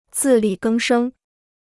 自力更生 (zì lì gēng shēng): regeneration through one's own effort (idiom); self-reliance.